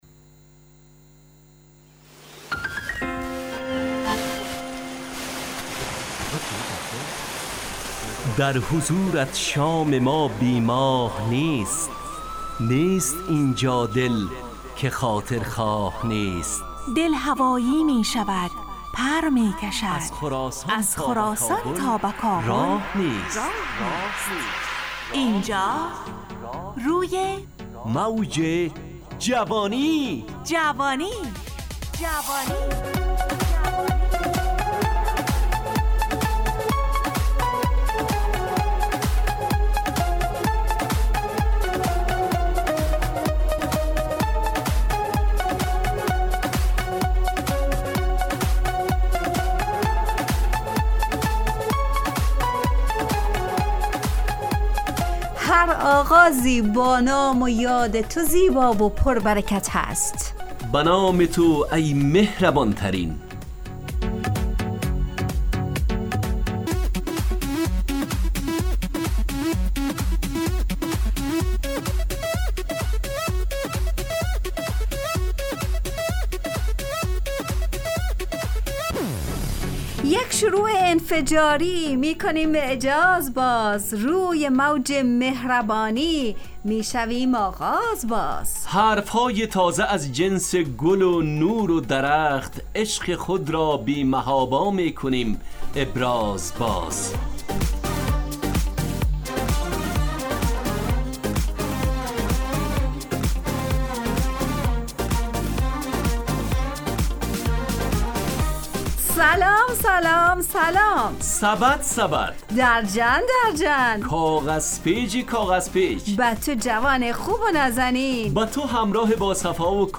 همراه با ترانه و موسیقی مدت برنامه 70 دقیقه . بحث محوری این هفته (قدم)